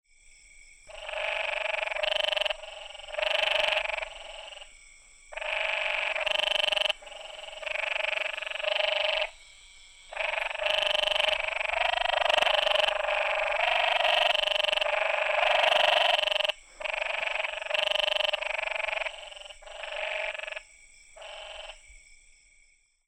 Advertisement Calls
Sound  This is a 23 second recording of the nocturnal advertisement calls of a small group of Canyon Treefrogs, some close and some distant, calling from a pool in a small creek in Santa Cruz County, Arizona in August  (shown to the right.) Insects and a Great Plains Narrow-mouthed Toad are heard in the background.
harenicolorcall.mp3